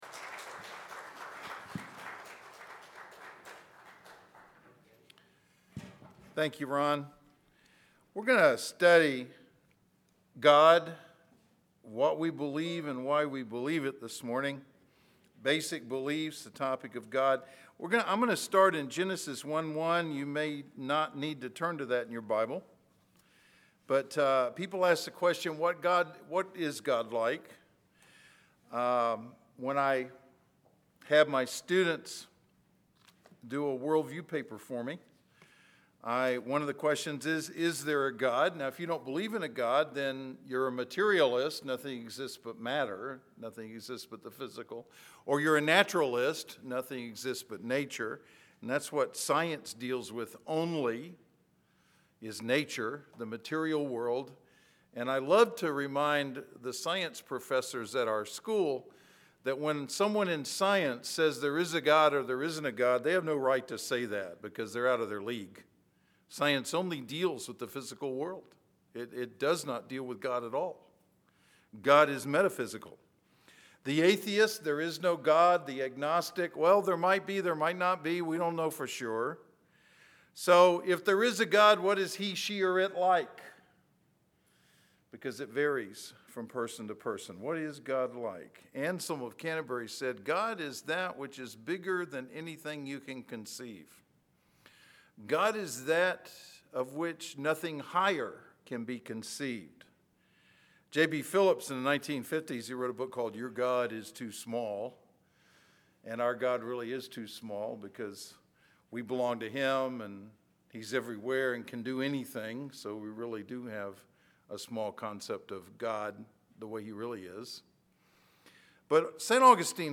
SERMONS | Inman Park Baptist Church